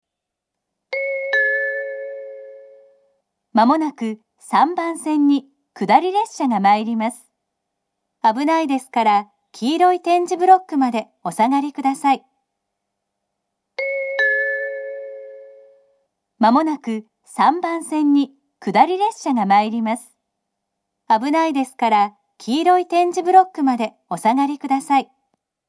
３番線下り接近放送